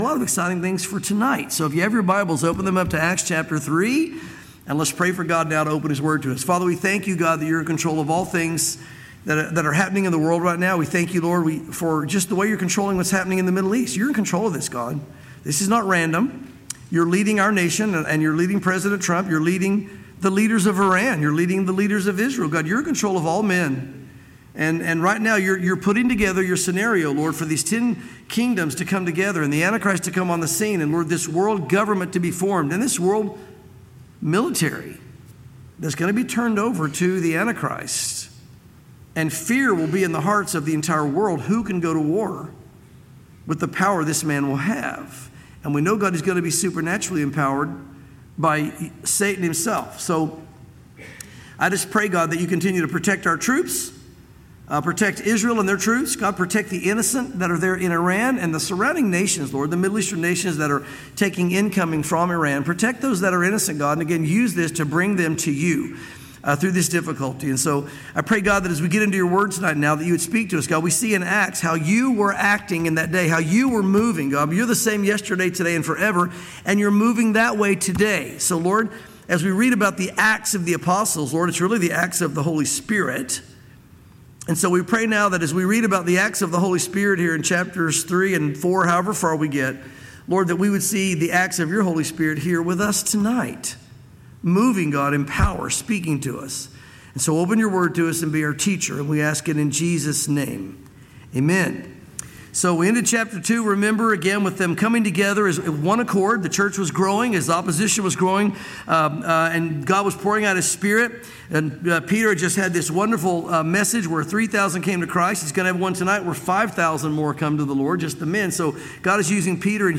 sermons Acts Chapters 3 & 4